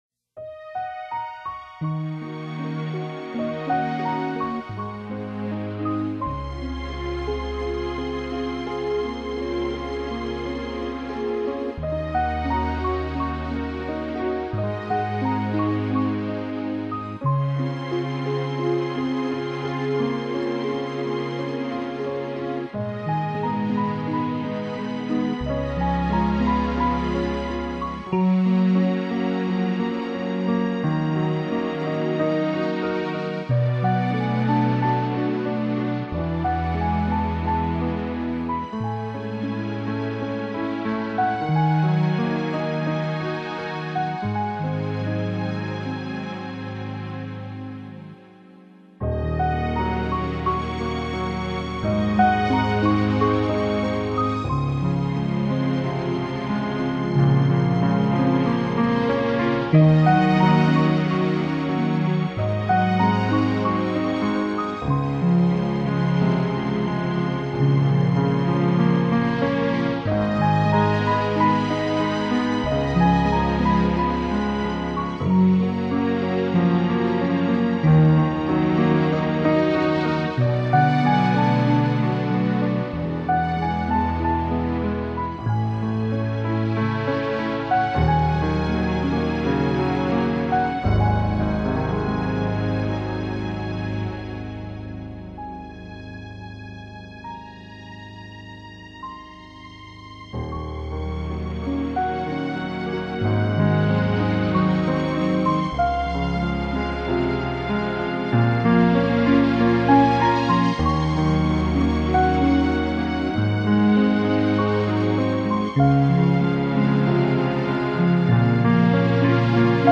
Genre: Piano, New Age, Relax